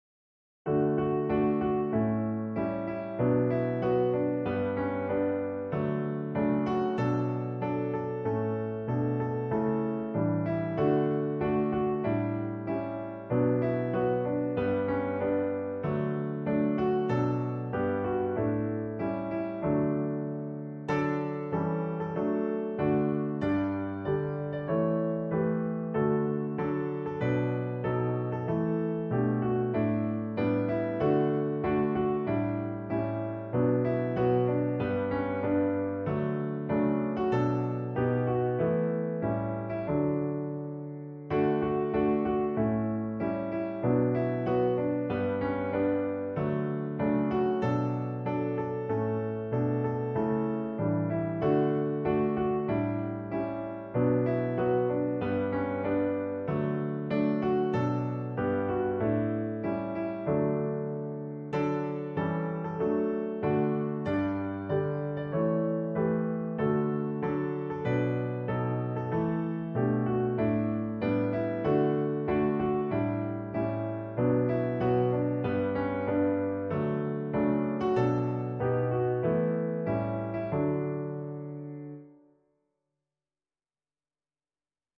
piano arrangement
for piano